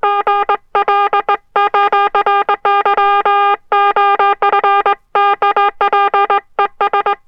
• morse code oscillator - medium pitched.wav
morse_code_oscillator_-_medium_pitched_PTv.wav